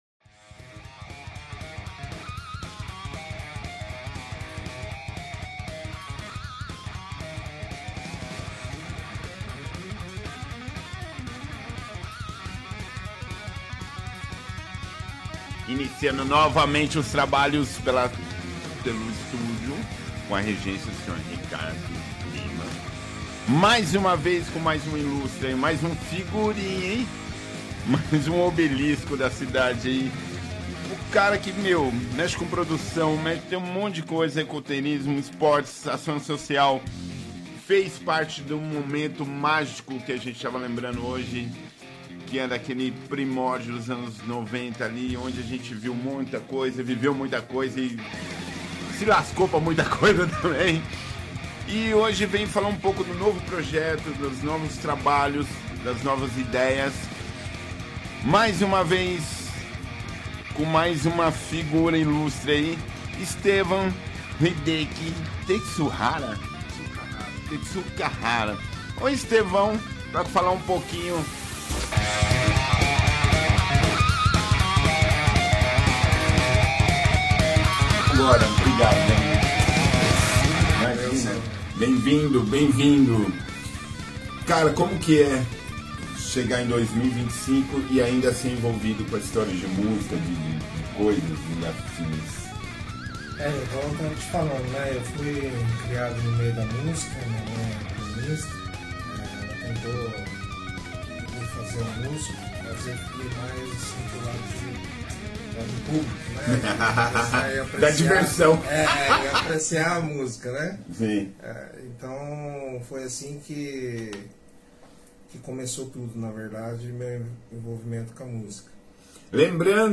Estúdios UEL FM 107.9